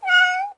小猫
描述：与小猫玩耍。
Tag: 小猫 动物 宠物喵